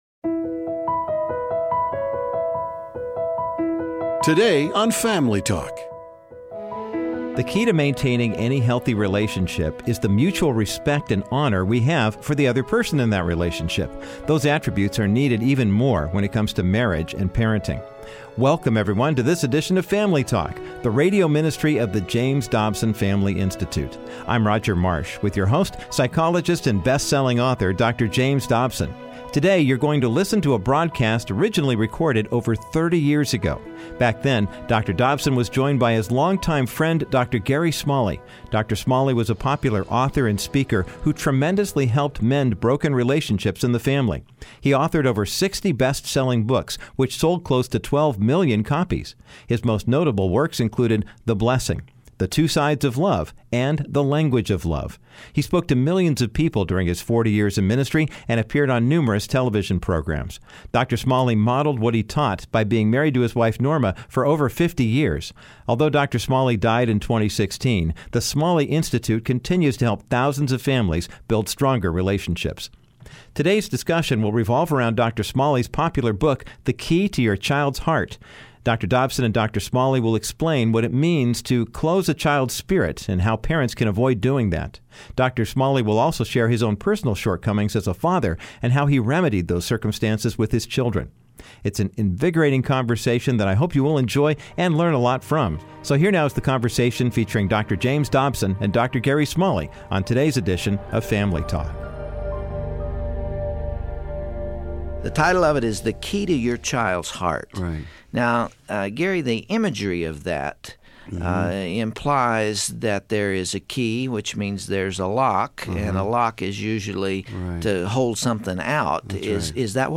Today, youll hear a classic conversation Dr. Dobson had with author and speaker, Dr. Gary Smalley. Dr. Smalley stresses the need for parents to be gentle and loving towards their kids, in all circumstances. The two also talk about how proper discipline can edify while still encouraging good behavior.